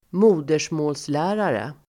Uttal: [²m'o:der_små:l]